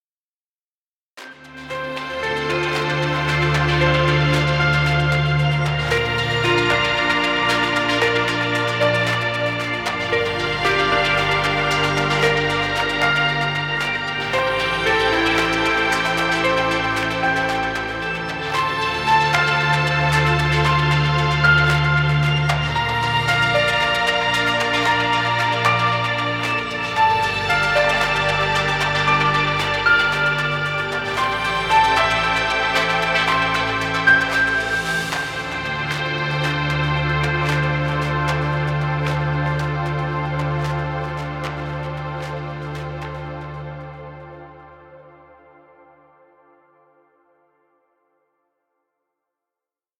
Ambient music. Background music Royalty Free.